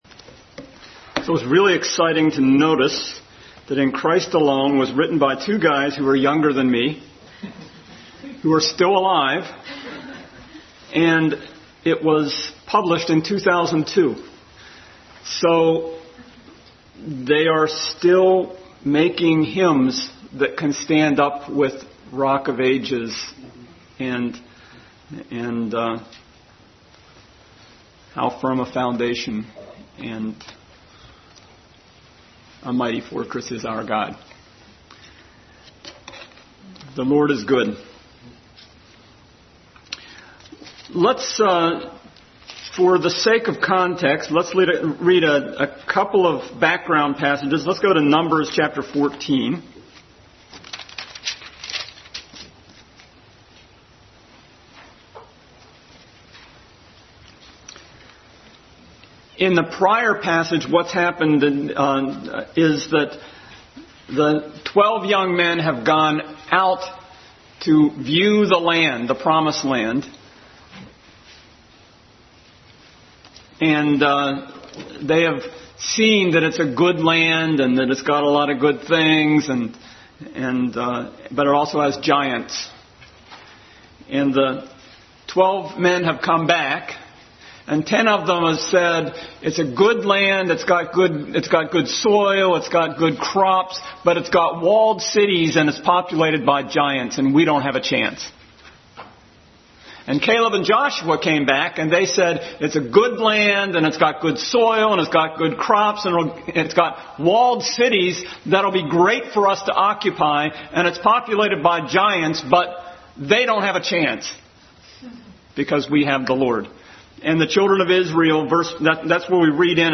1 Corinthians 10:7-33 Passage: 1 Corinthians 10:7-33, Numbers 14:1-3,27-31, Exodus 32:1-14, 1 Corinthians 1:9 Service Type: Family Bible Hour Family Bible Hour Message.